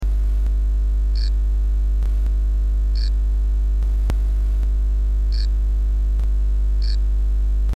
sa chante sa chante!!
Pour l'enregistrement, je pense utilisé un micro cravate, reconnecter a un pré amplis puis a mon pc, le tout enregistrer avec audacity.
Et voila pour le chant :
amazonicus1.mp3